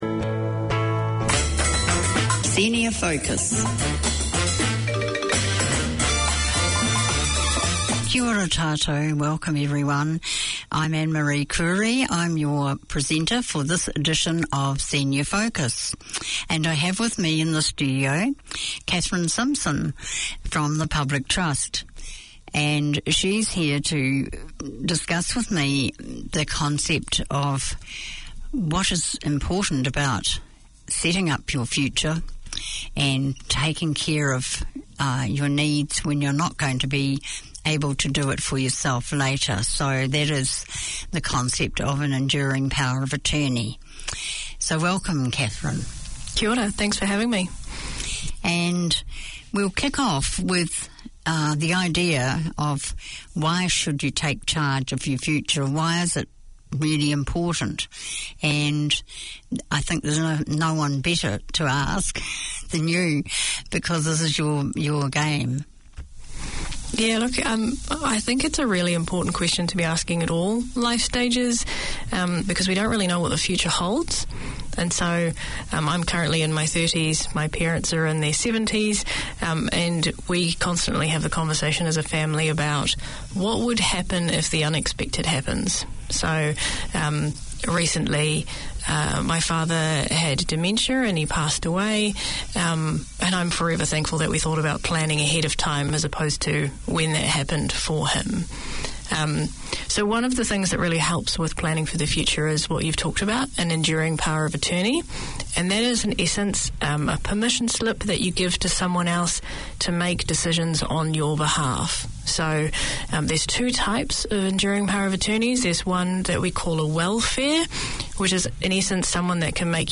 This show talks about science topics and their relevance to our everyday lives in a language that is understandable to the person on the street. Fascinating discussions are delivered along with ‘hot-off-the-press’ science news and a curious selection of the favourite music of scientists.